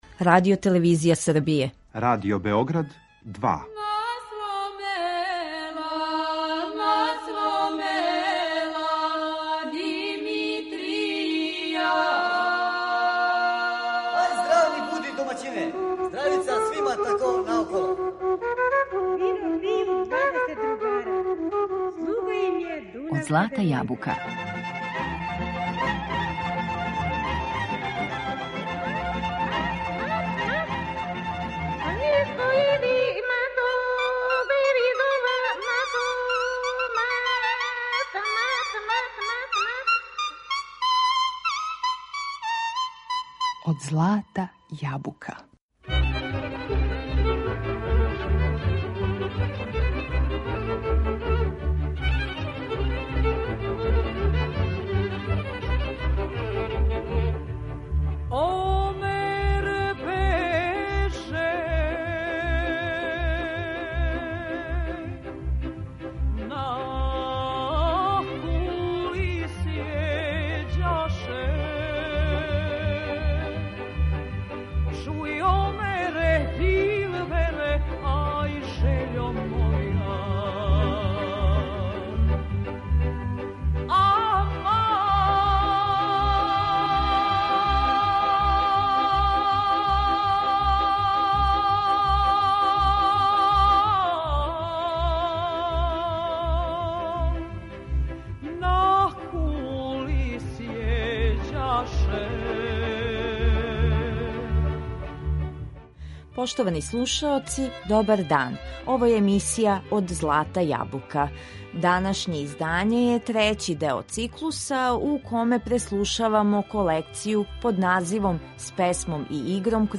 У данашњем издању емисије Од злата јабука, уз помоћ магије звучног архива, враћамо се у 1977. годину.
Овај звучни преглед музичких дијалеката тадашње земље објединио је записе најбољих вокалних извођача и народних ансамбала.